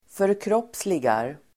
Uttal: [förkr'åp:sligar]